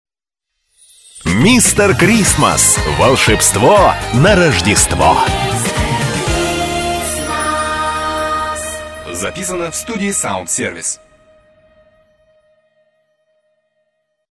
Джингл